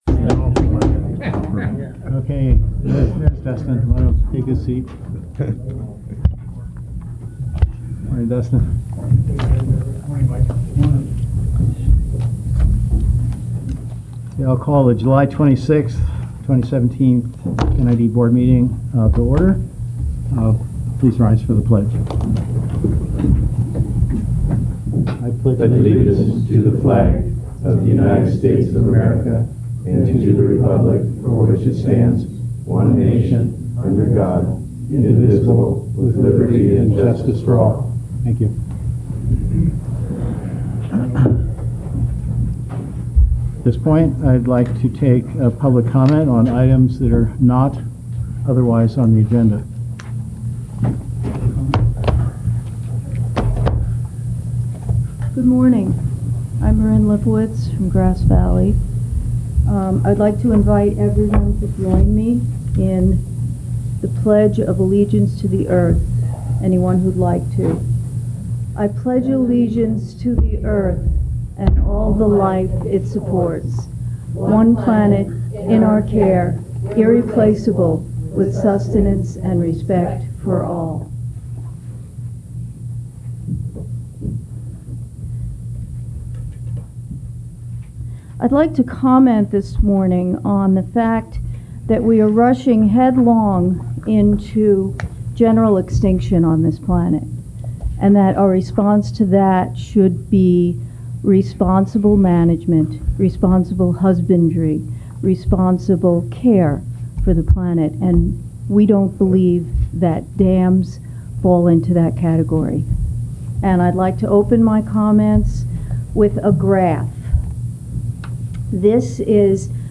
Board of Directors Meeting